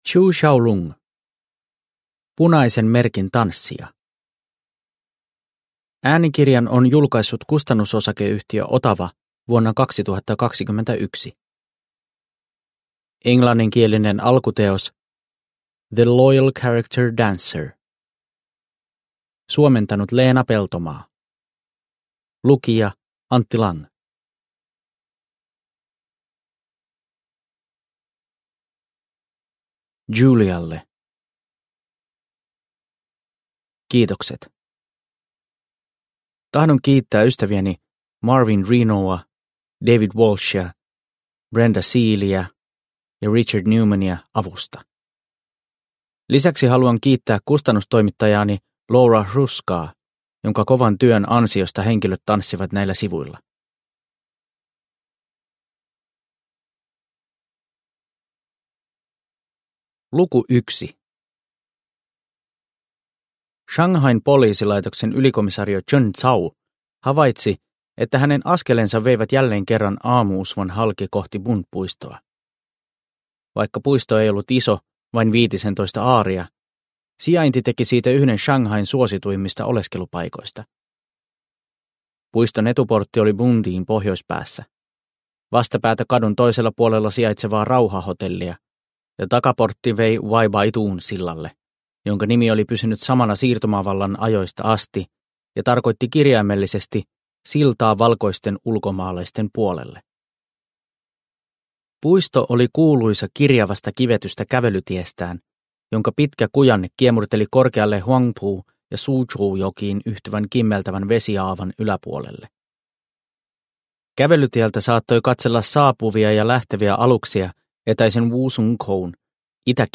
Punaisen merkin tanssija – Ljudbok – Laddas ner